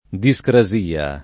discrasia [ di S kra @& a ]